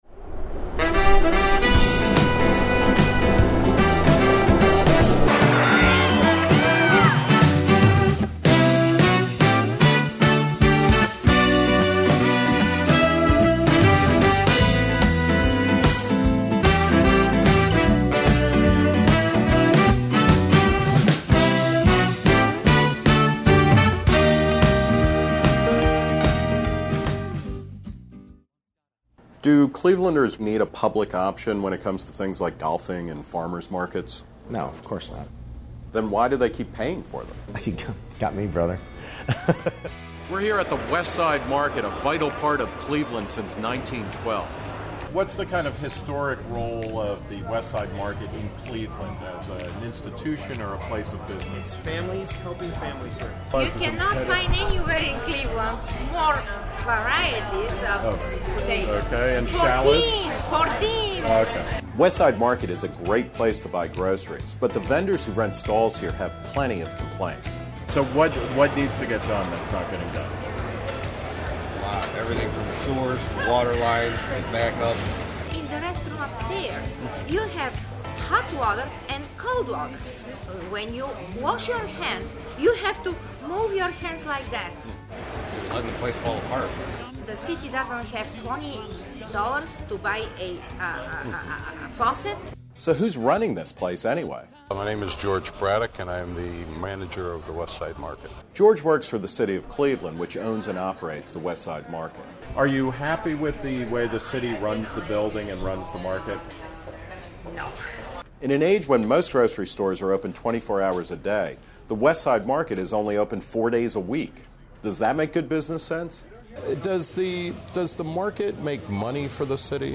narrated by Nick Gillespie; music by the Cleveland band Cats on Holiday.